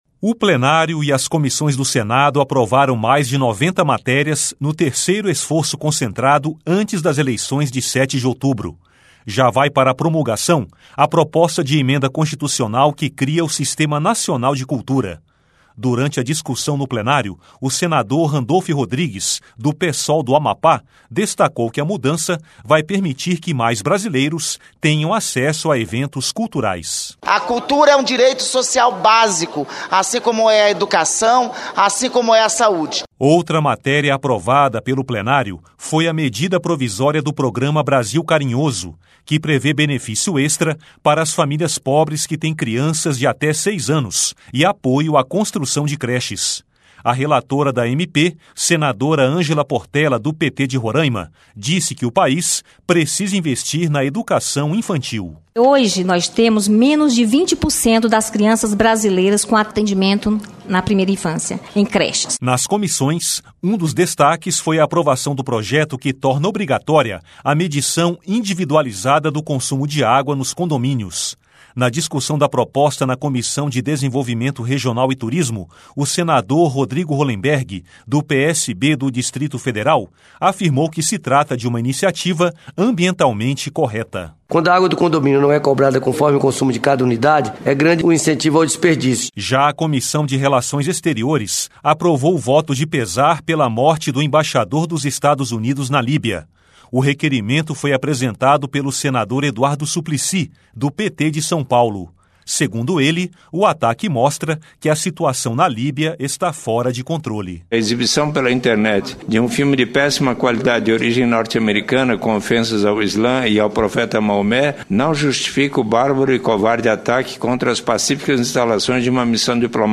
Rádio Senado
A REPORTAGEM